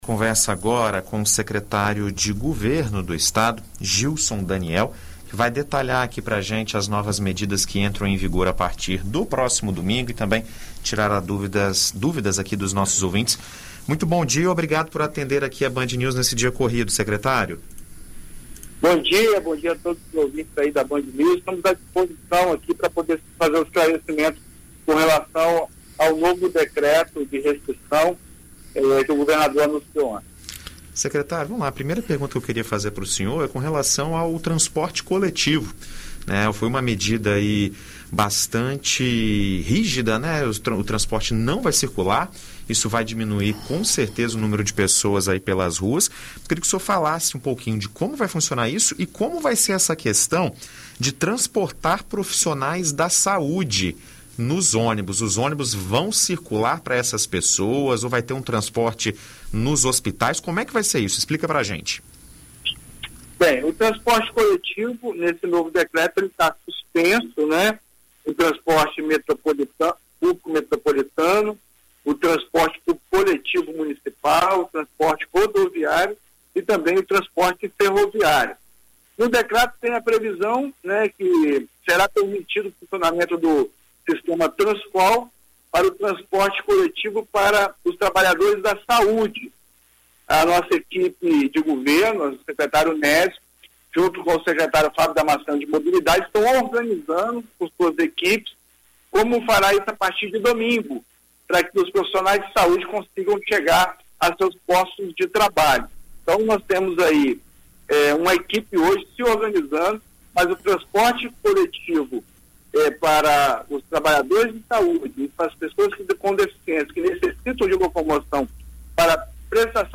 Em entrevista à BandNews FM, o secretário de Estado, Gilson Daniel, detalha as novas restrições da quarentena e esclarece que alguns estabelecimentos permanecerão abertos, como é o caso do supermercados.